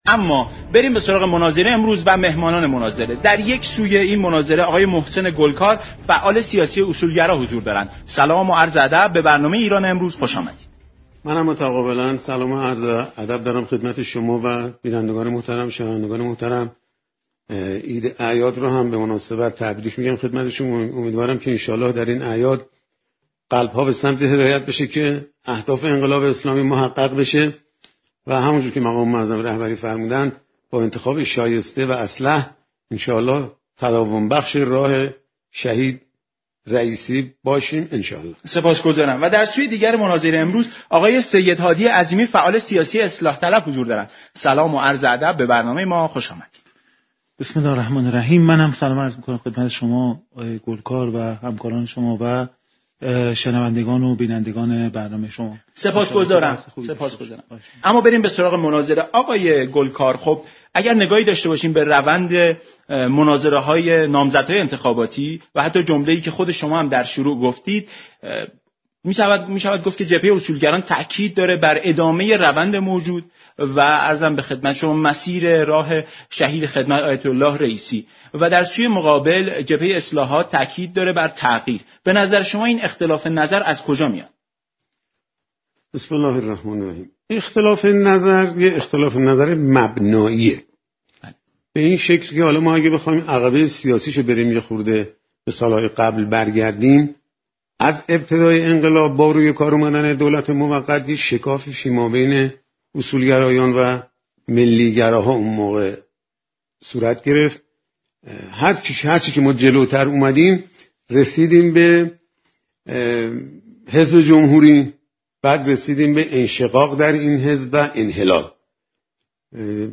رویداد
مناظره رادیویی